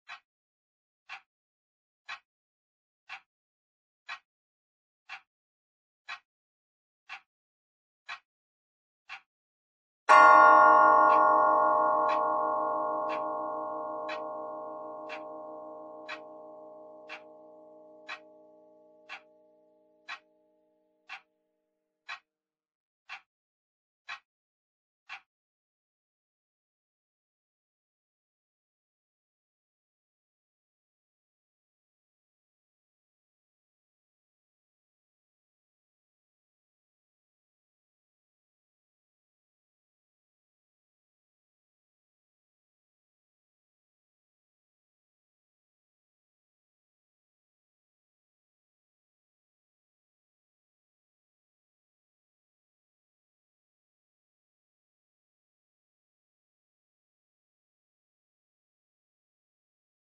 Звук тиканья часов в разных вариантах
Идут настенные часы с боем